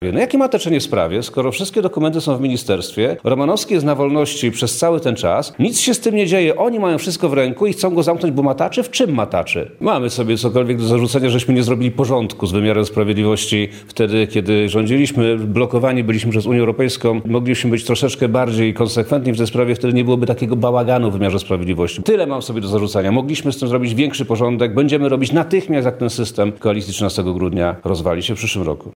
Poseł z Lubelskiego trafi do aresztu Do tej argumentacji podczas konferencji w Lublinie odniósł się poseł PiS Przemysław Czarnek: – Jak możliwe jest mataczenie w tej sprawie, skoro wszystkie dokumenty są w ministerstwie, a Romanowski był przez cały czas na wolności?